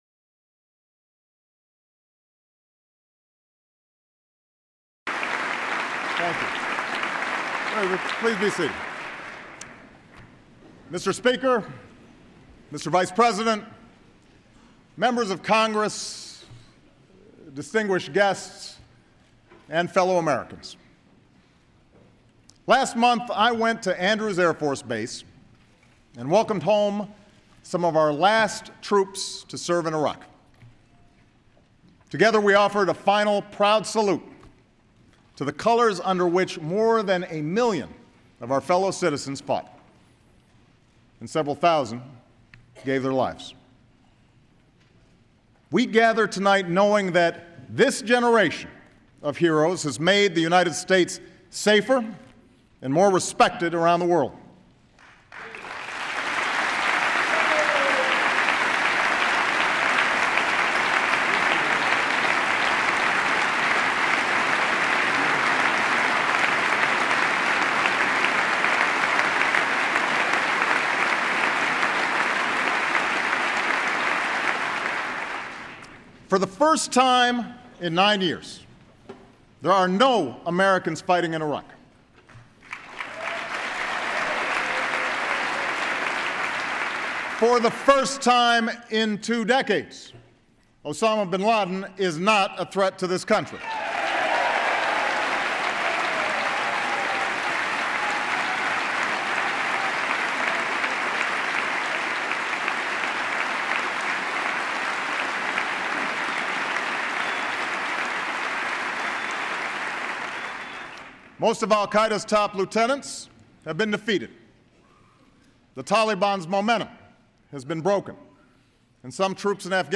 January 24, 2012: 2012 State of the Union Address